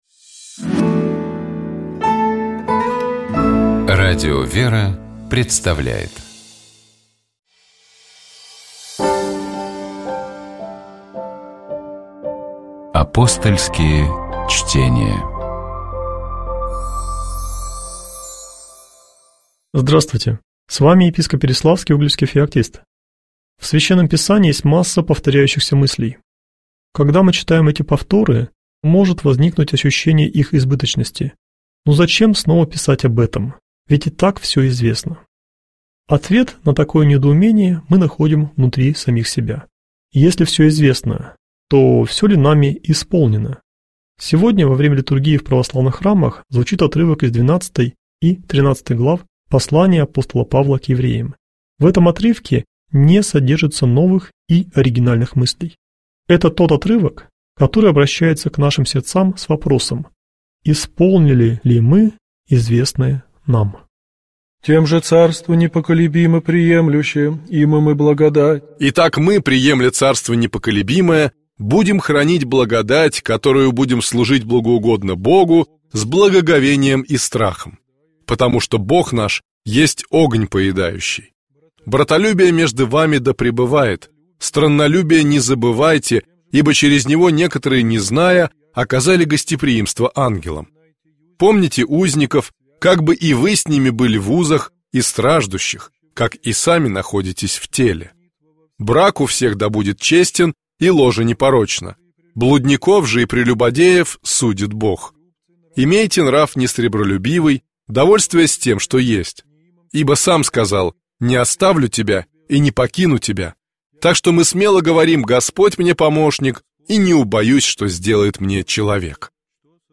У нас в студии была искусствовед, кандидат культурологии